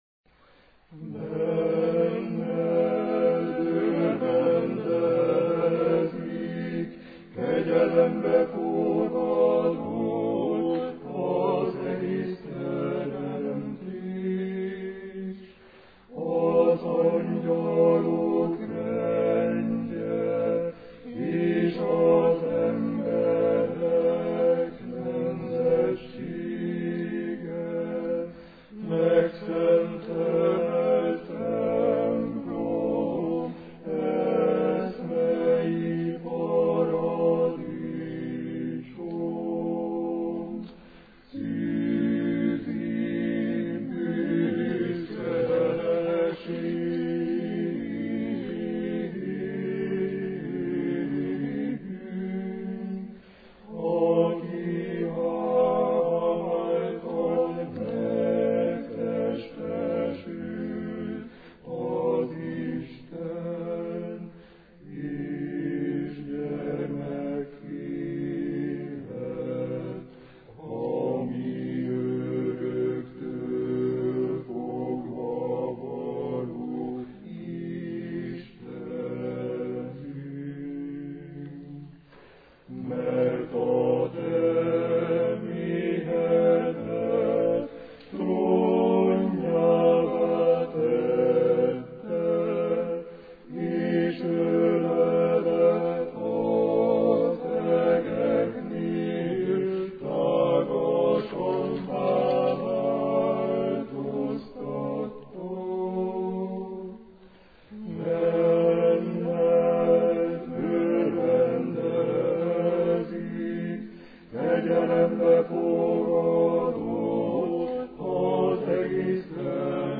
Egyházzenei koncert a miskolci Szentháromság templomban
A második fellépő, a Szent Demeter Kamarakórus a legszebb bizánci énekekből mutatott be néhányat a hallgatóknak, magyar és görög nyelven. A kórusok méltán arattak sikert fellépésükkel.